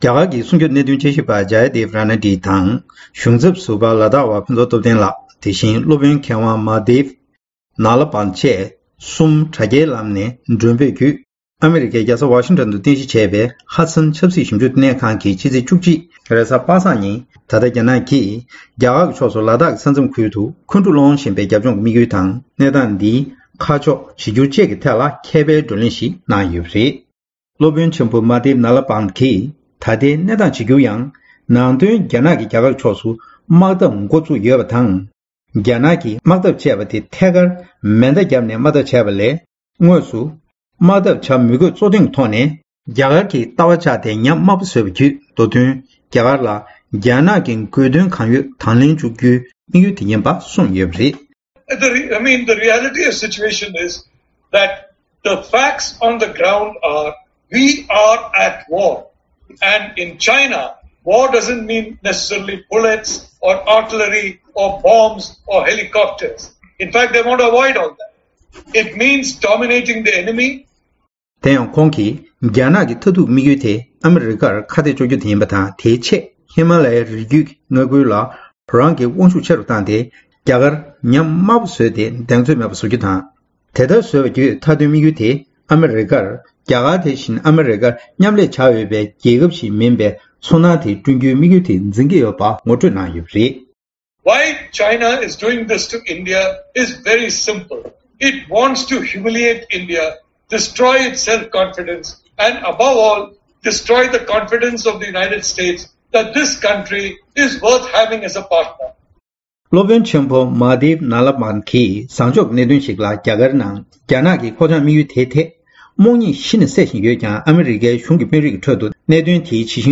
རྒྱ་དཀར་ནག་གཉིས་ཀྱི་ས་མཚམས་དཀའ་རྙོག་ཟེར་བའི་ཐོག་མཁས་པའི་བགྲོ་གླེང་།
སྒྲ་ལྡན་གསར་འགྱུར།